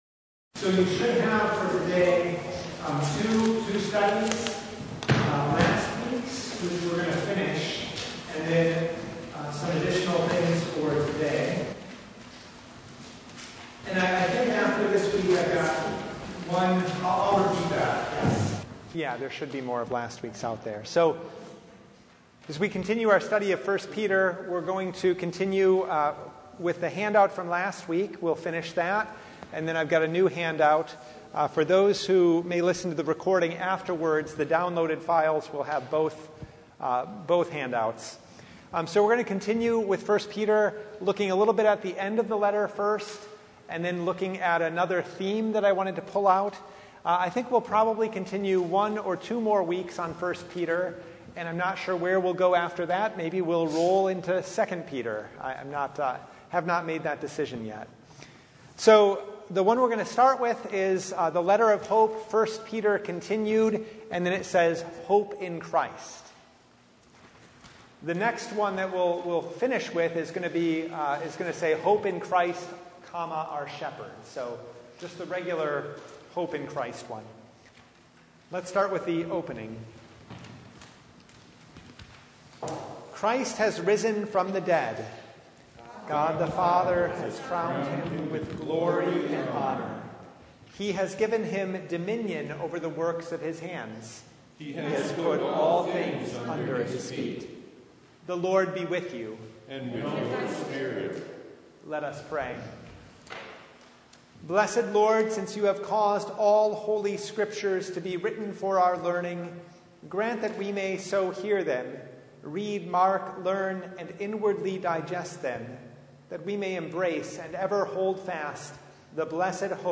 Service Type: Bible Study